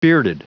Prononciation du mot bearded en anglais (fichier audio)
Prononciation du mot : bearded